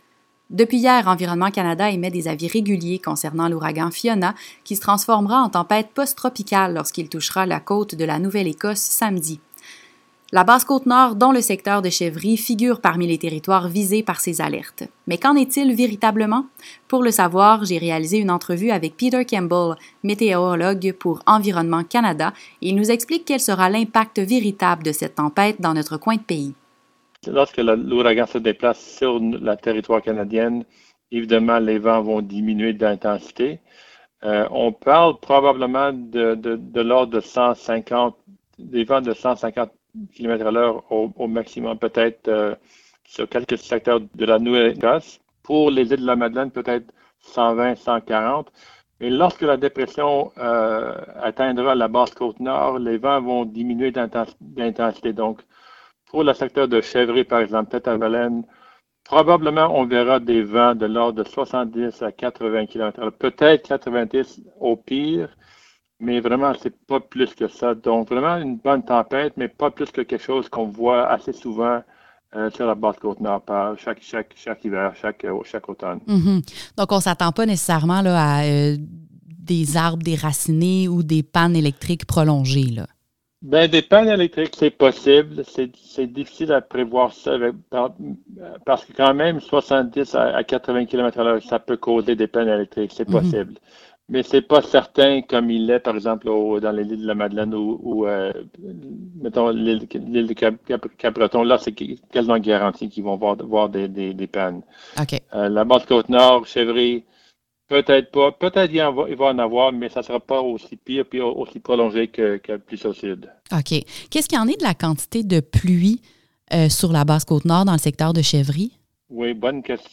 Fiona-segment-radio.mp3